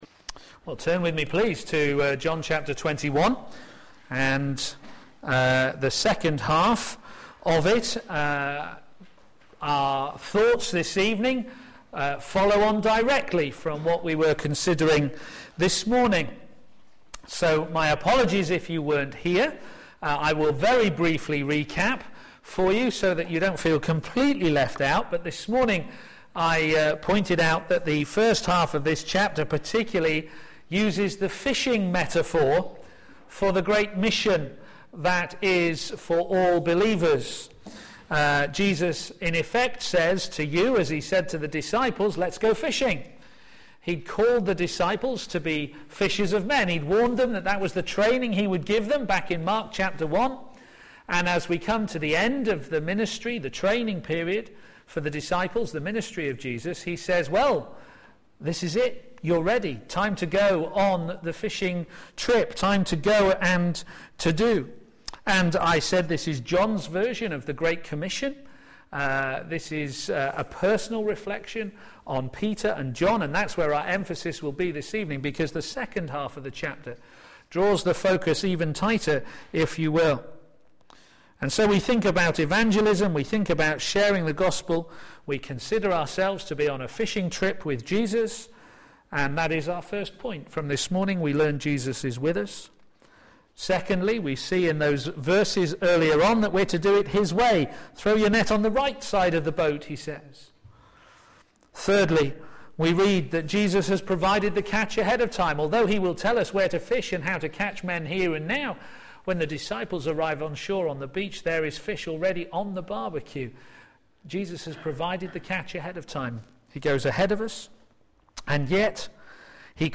p.m. Service
Series: John on Jesus Theme: A glorious reinstatement to true discipleship Sermon